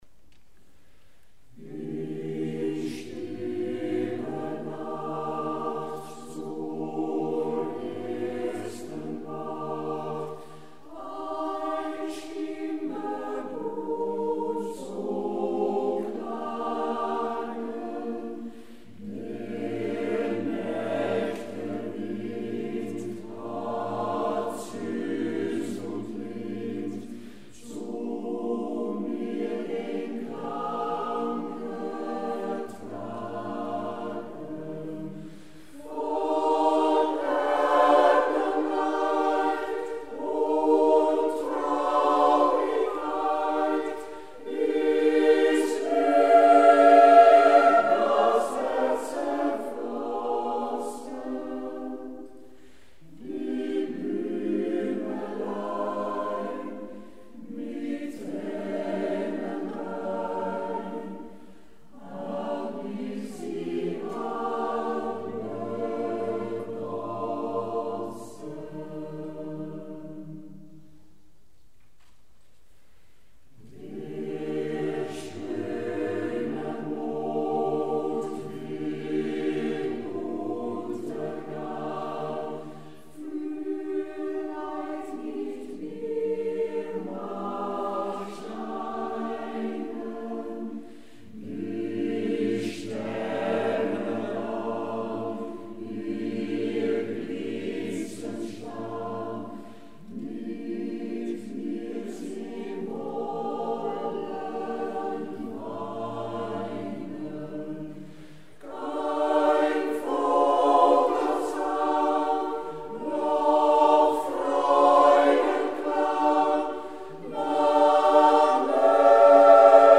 Luisteren naar Arpeggio – Kamerkoor Arpeggio
Concert Johannes Brahms, Alt Rapsodie en andere liederen, Adventskerk Assen 10 november 2018